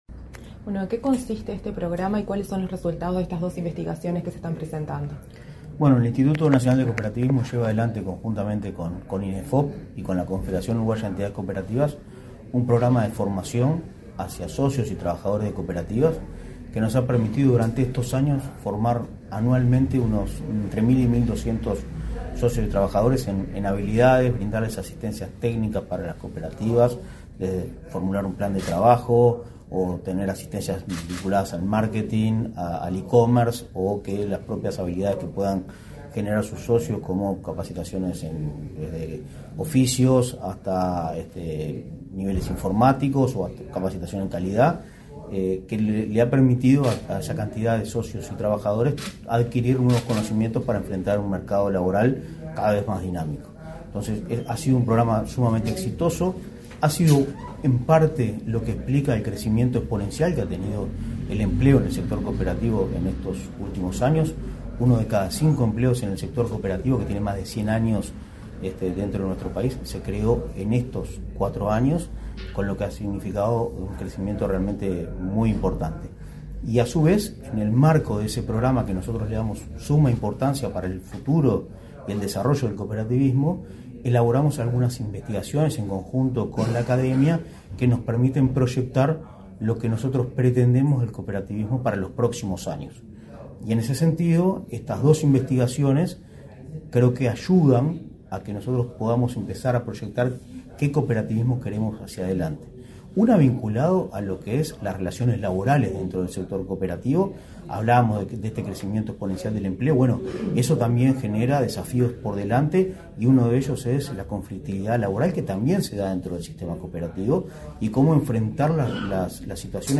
Entrevista al presidente de Inacoop, Martín Fernández
Los institutos nacionales de Empleo y Formación Profesional (Inefop), de Cooperativismo (Inacoop) y la Confederación Uruguaya de Entidades Cooperativas (Cudecoop) presentaron, este 23 de julio, dos investigaciones en el marco del Programa de Formación Cooperativa. El titular de Inacoop, Martín Fernández, en declaraciones a Comunicación Presidencial, destacó los estudios realizados.